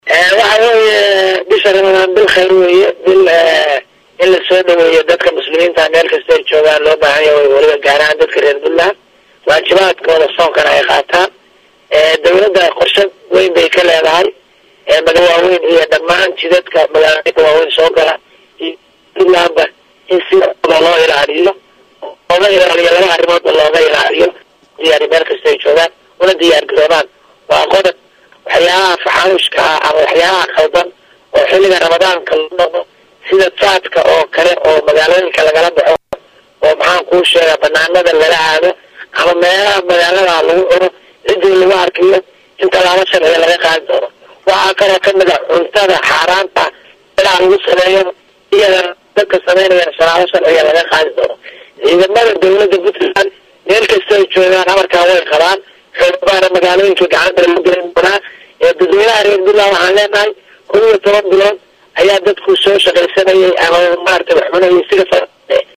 Wasiirka Amniga ee Maamulka Puntland Cabdi Cali Xirsi Qarjab oo arintaas ka hadlaya hadaladiisa waxaa ka mid ahaa.